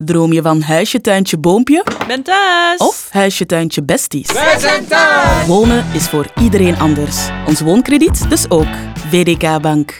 vdk Bank-Woonkrediet-radio-nl-10s.wav